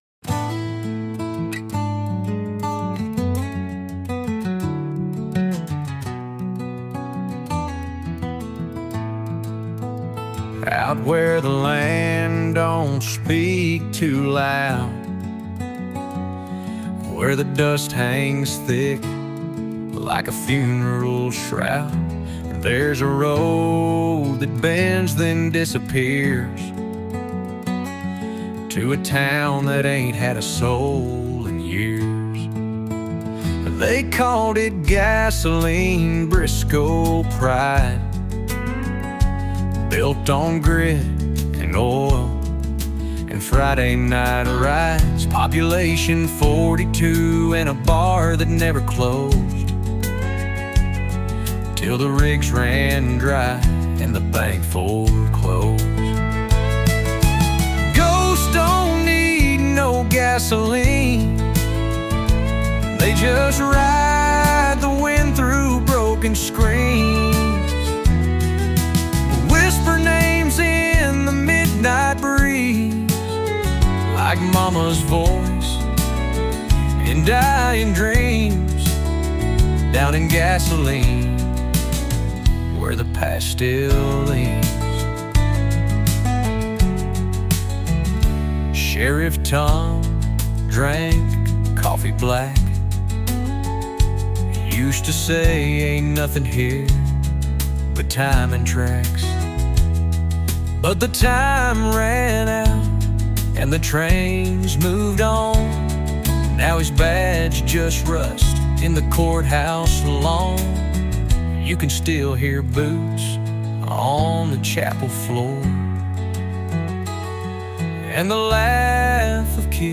Gasoline (The Ghost of Briscoe) is a haunting folk ballad that tells the story of a forgotten Texas town swallowed by time and silence. Through vivid imagery, dusty melodies, and a deeply nostalgic tone, the song paints a portrait of Gasoline — once a small but thriving community in Briscoe County, now nothing more than creaking wood and desert wind.